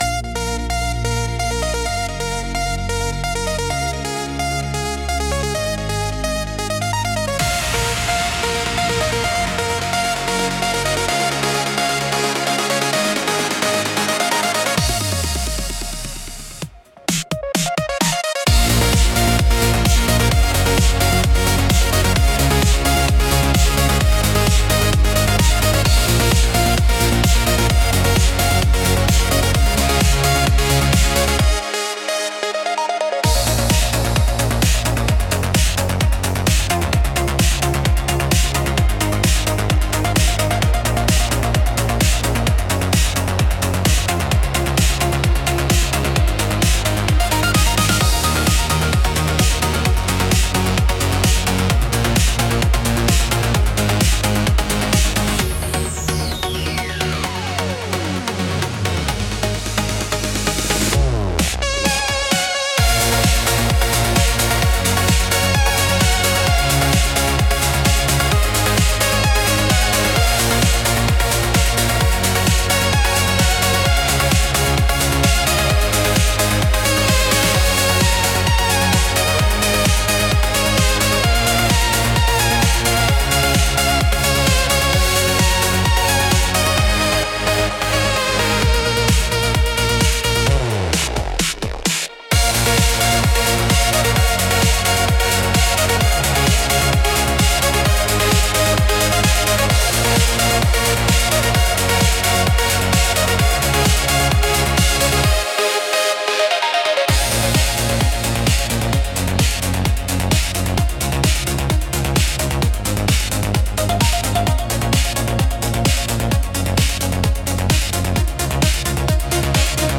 Genre: Electrical Mood: Hype Editor's Choice